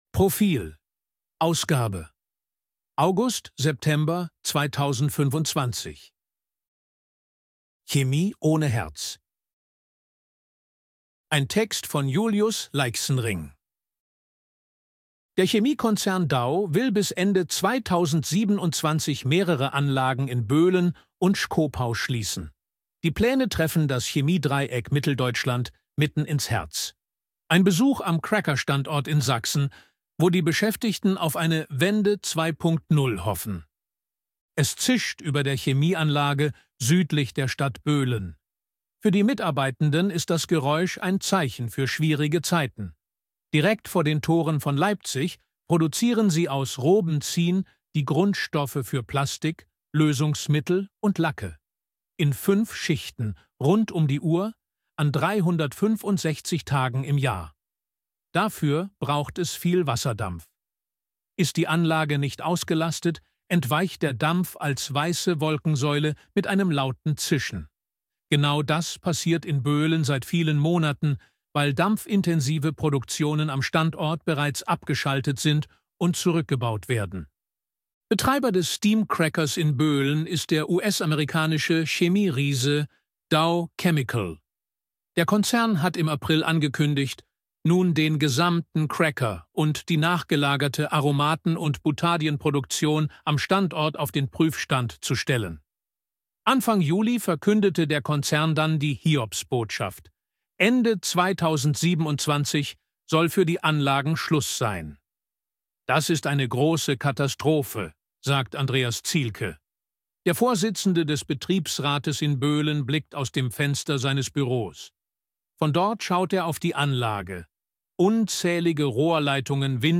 Artikel von KI vorlesen lassen ▶ Audio abspielen
ElevenLabs_252KI_Stimme_Mann_Reportage.ogg